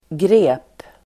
Uttal: [gre:p]